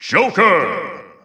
The announcer saying Joker's name in English and Japanese releases of Super Smash Bros. Ultimate.
Joker_English_Announcer_SSBU.wav